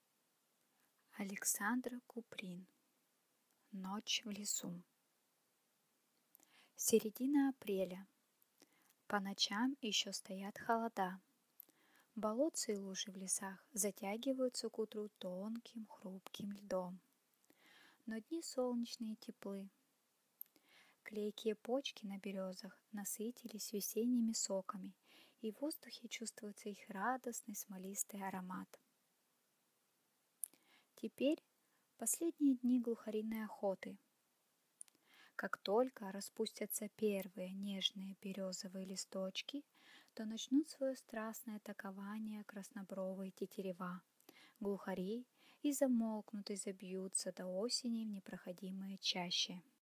Аудиокнига Ночь в лесу | Библиотека аудиокниг